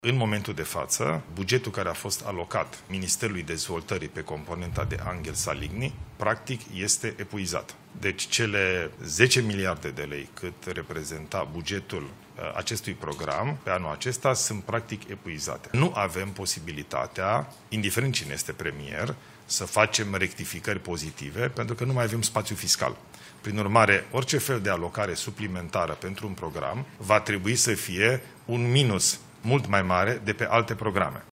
Bugetul programului „Anghel Saligny” a fost epuizat deja și nu mai putem suplimenta cu alți bani, spune premierul Ilie Bolojan